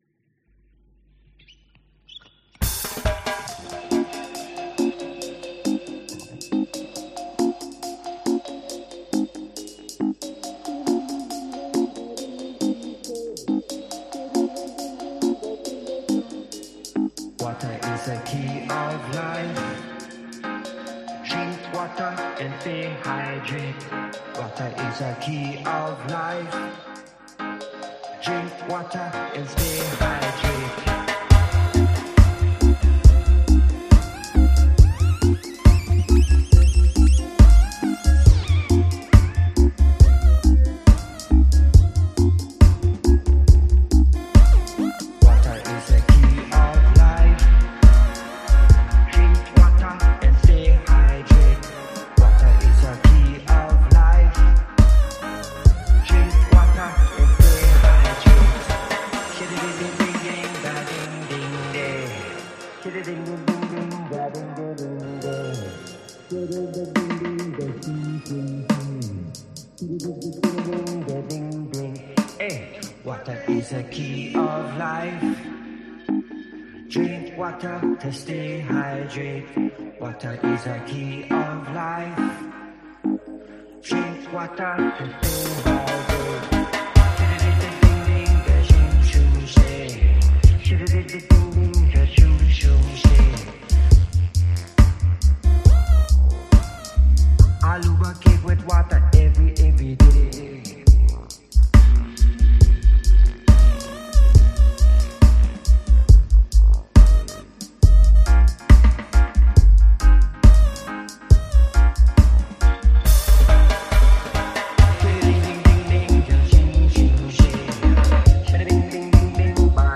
Genre: Steppers, Reggae, Dub